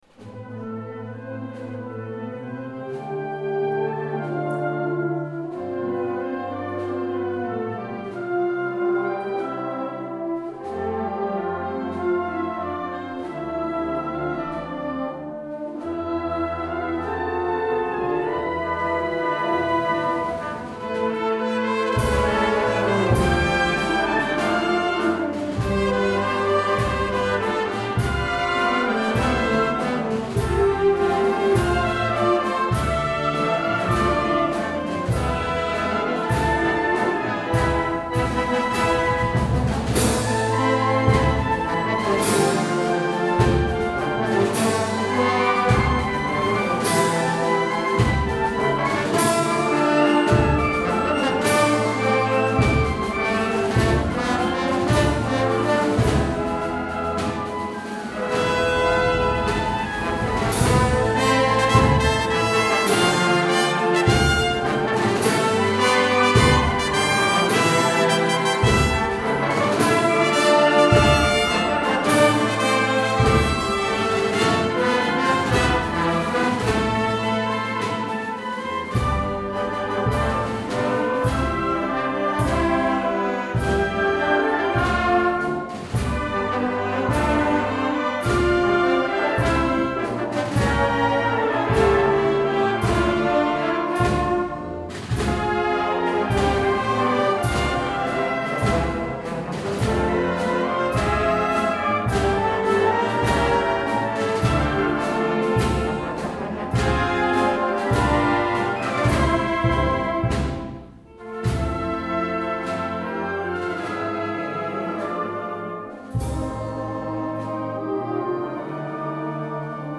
Concert de Setmana Santa - Auditori de Porreres.